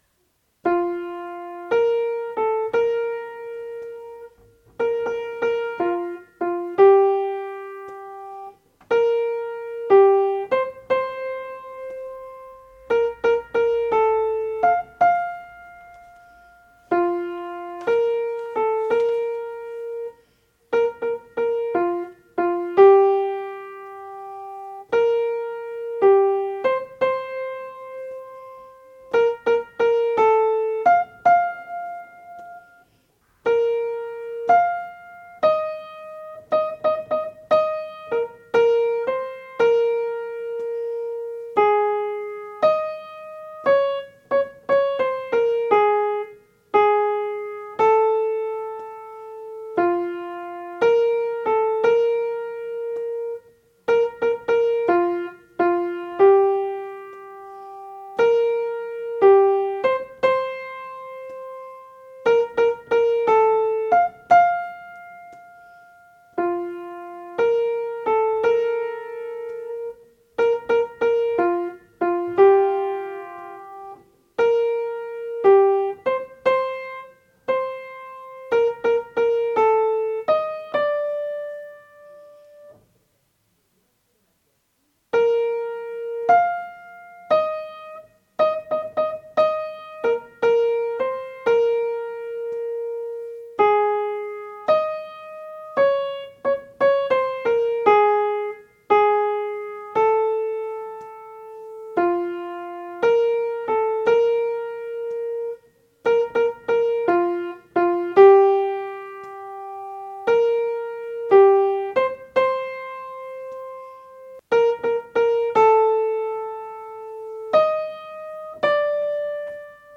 tenor
Chanson_de_Lara_tenors.mp3